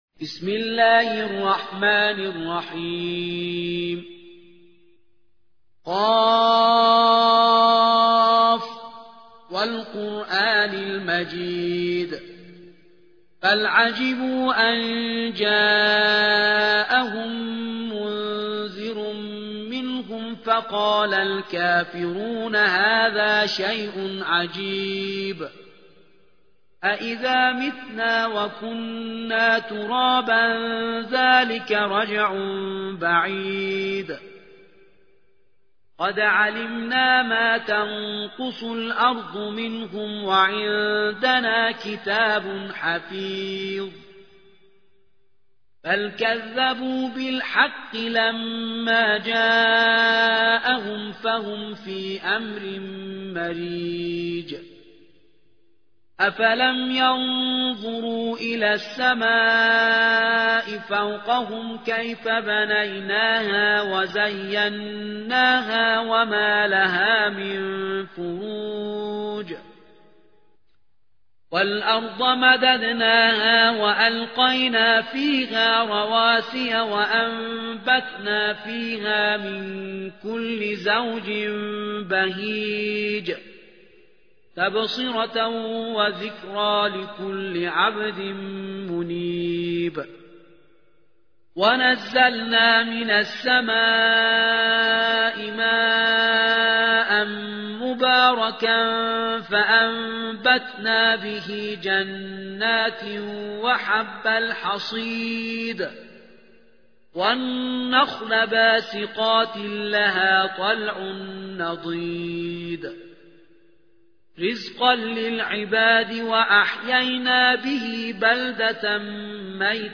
50. سورة ق / القارئ